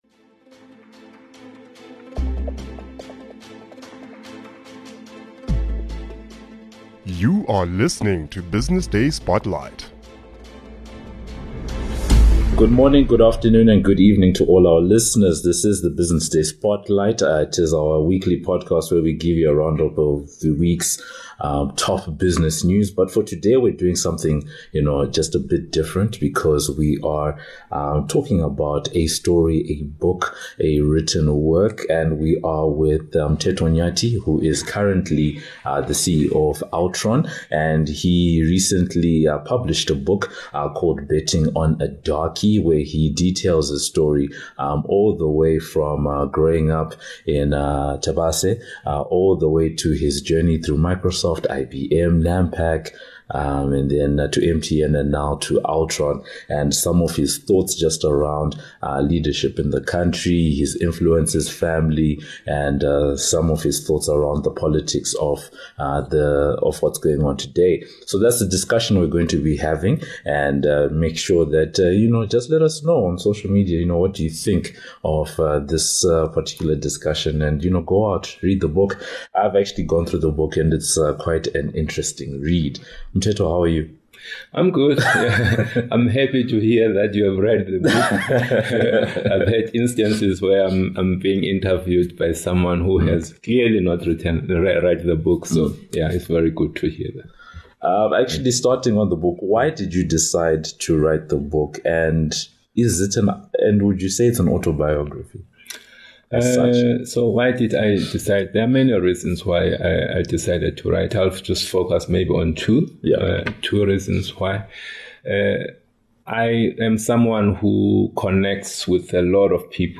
at Altron’s head office in Johannesburg